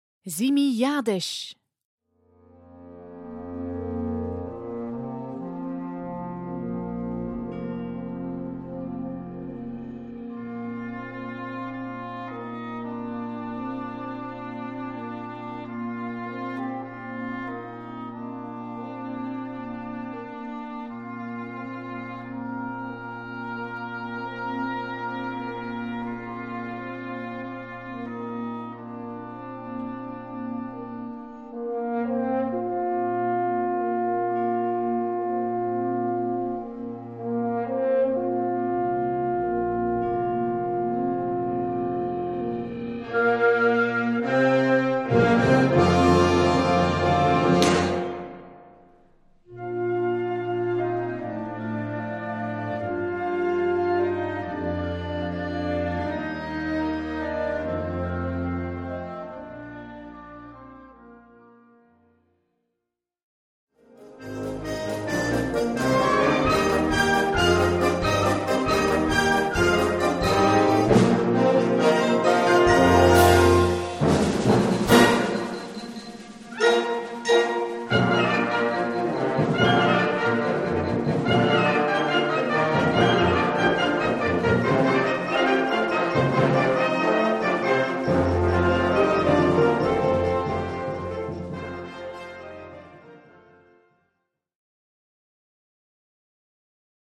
Besetzung: Blasorchester
Klezmer-Spielweise im Mittelpunkt steht.